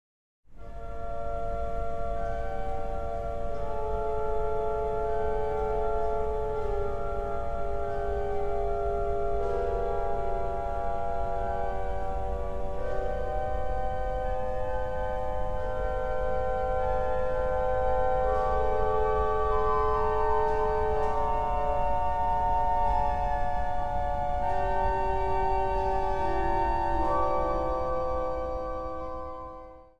Prachtige orgelmuziek, met het thema 'kerst'.